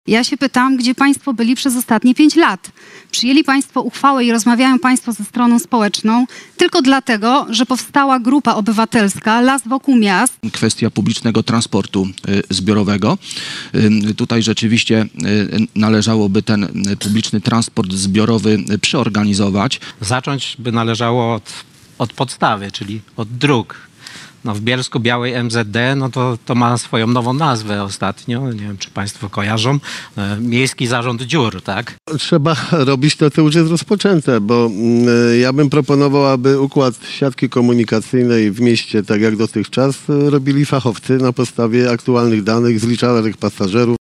Wczoraj (04.04) w auli Uniwersytetu Bielsko-Bialskiego odbyła się debata z udziałem kandydatów na prezydenta Bielska-Białej.